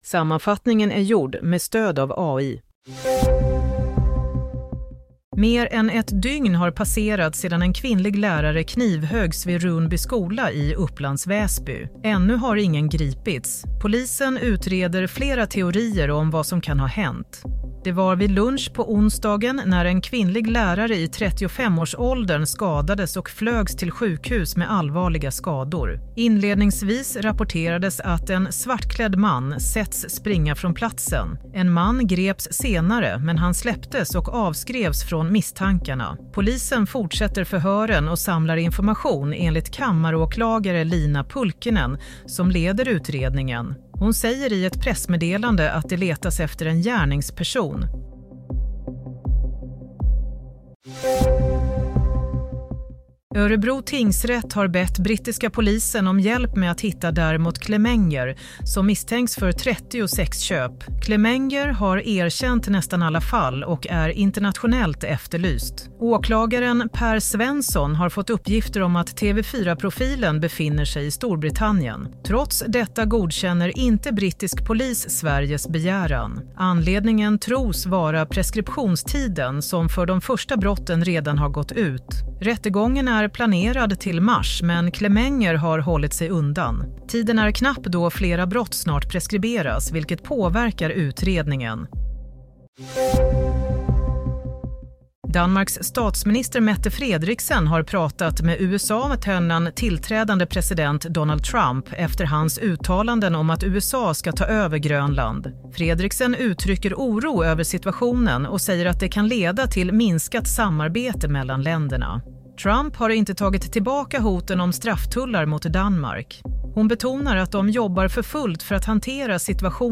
Nyhetssammanfattning 16 januari 16.00